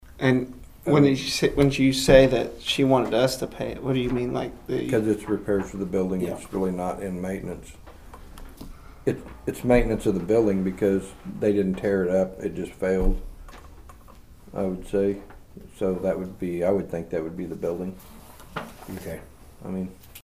The Nowata County Commissioners met for their weekly meeting on Monday morning at the Nowata County Courthouse Annex.
The commissioners discussed their role in funding the latest improvements